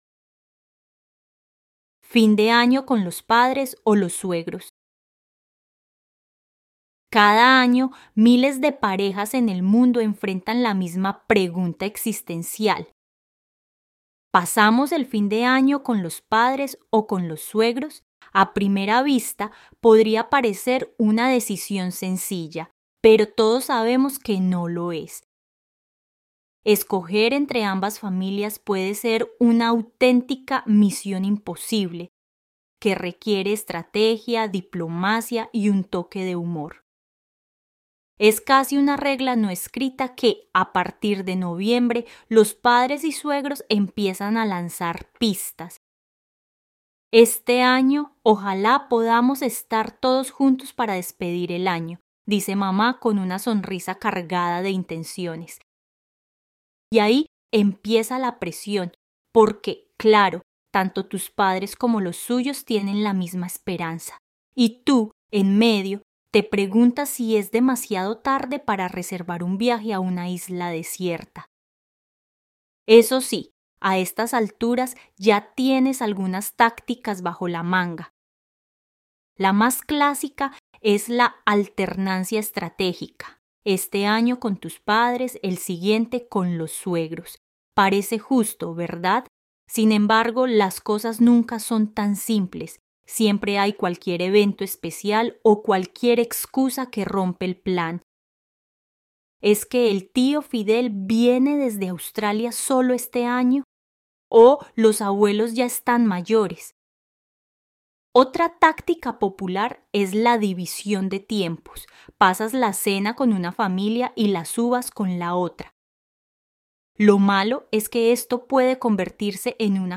Spanish online reading and listening practice – level B2